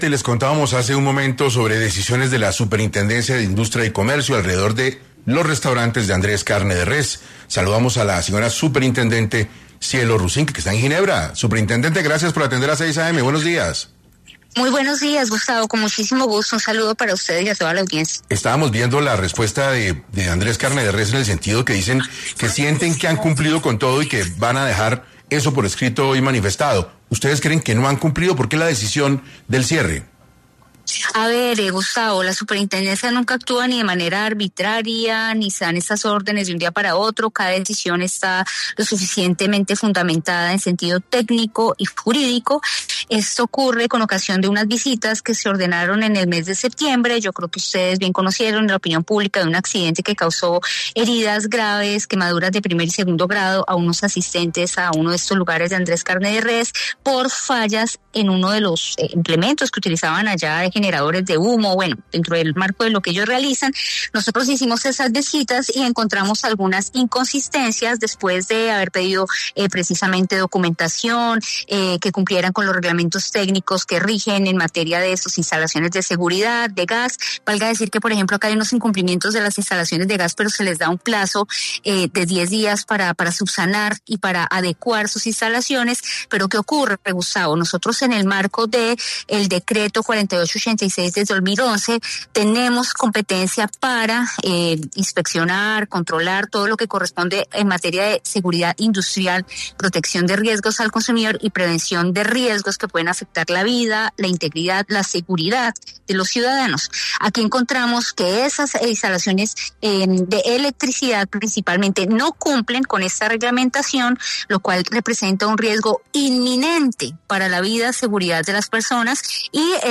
La superintendente de Industria y Comercio, Cielo Rusinque, pasó por 6AM para explicar las razones por las que se ordenó el cierre inmediato de los establecimientos Andrés D.C. y Andrés Carne de Res.